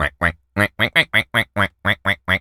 Animal_Impersonations
duck_quack_seq_long_02.wav